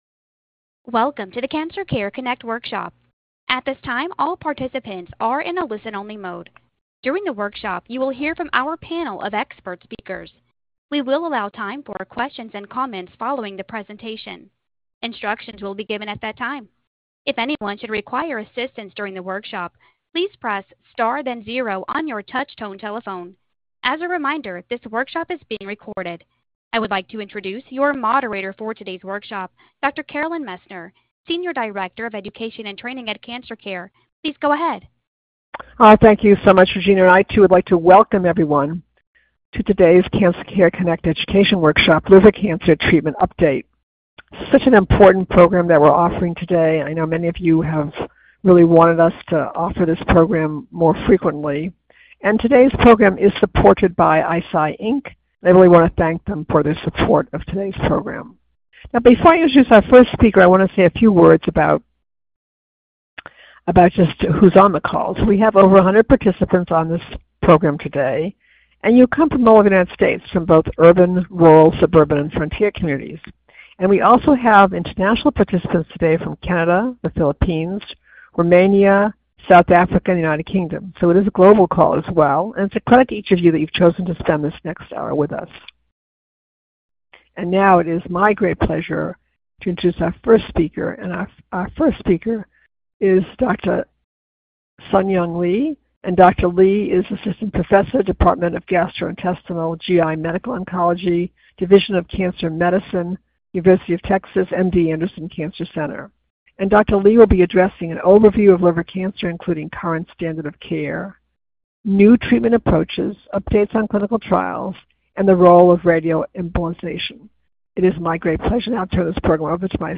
Questions for Our Panel of Experts